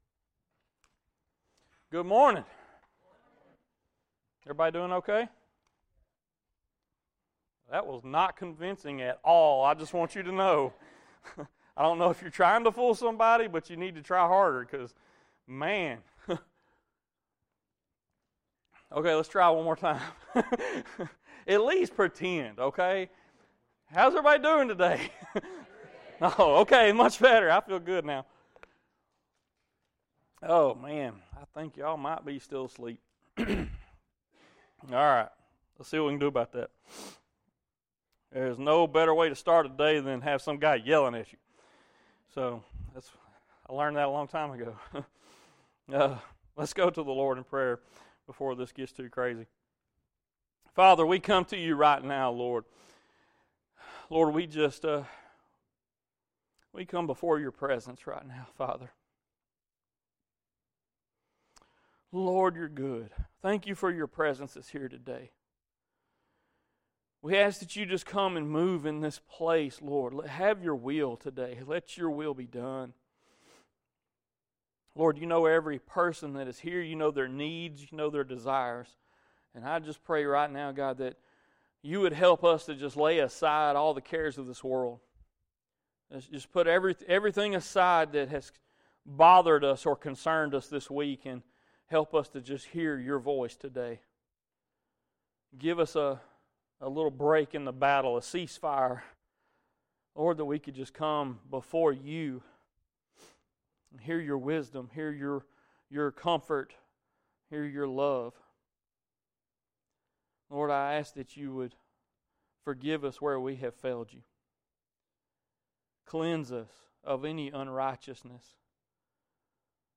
Download our weekly sermons from our church service.